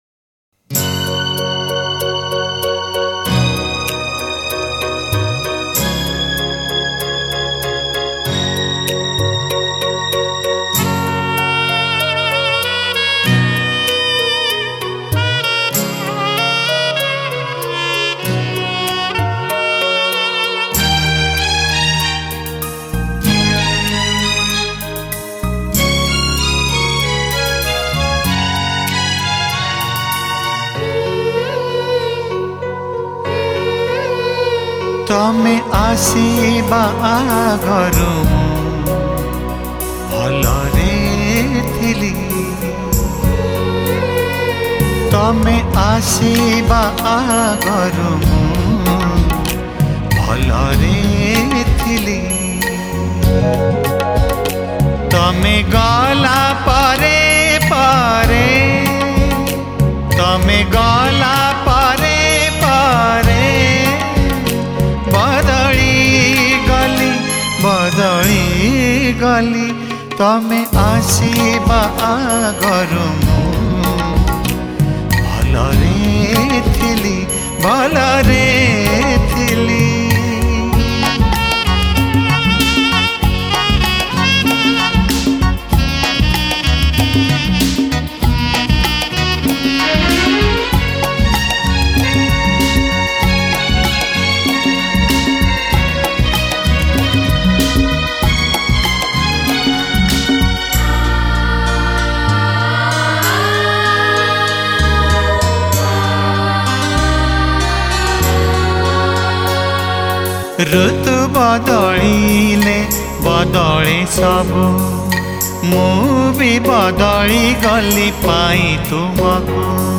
Odia Old Demand Album Sad Songs